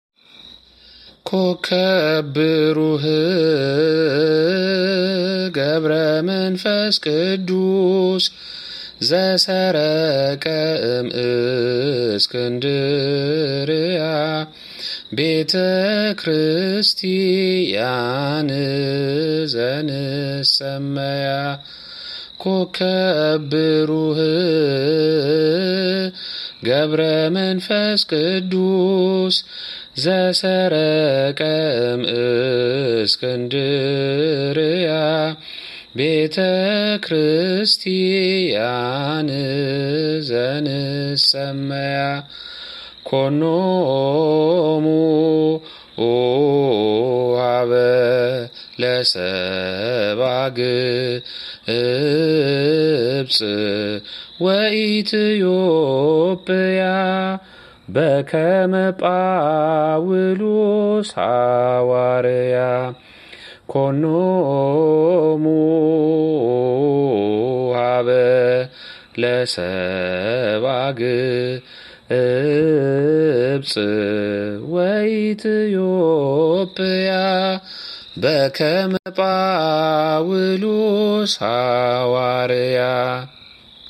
መዝሙር (ኮከብ ብሩህ) May 13, 2018
Re'ese Adbarat Tserha Aryam Kidist Selassie Cathedral Ethiopian Orthodox Tewahedo Church --- Minneapolis, Minnesota